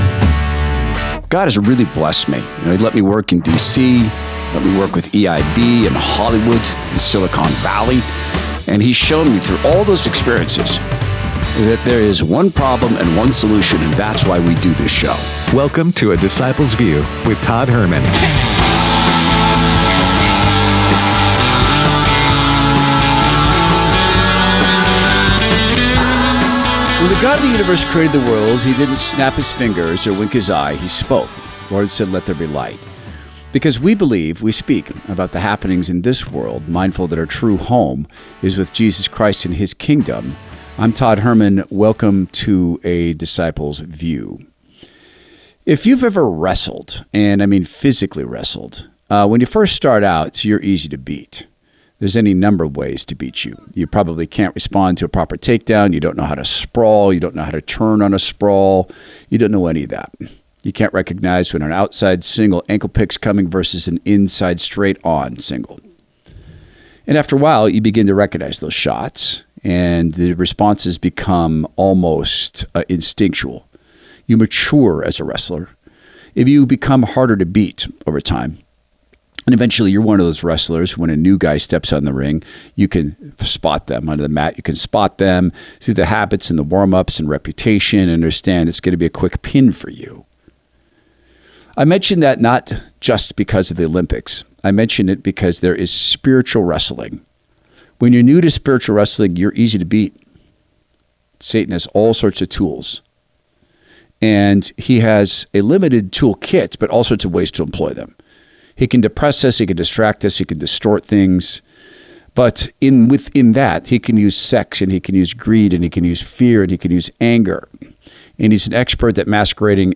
Donald Trump Speaks At Black Journalists Convention